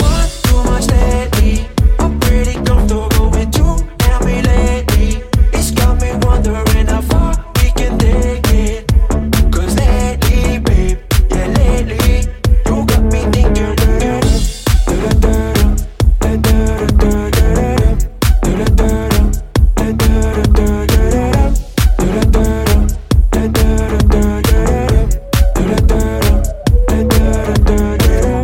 Genere: pop,house, deep, club, remix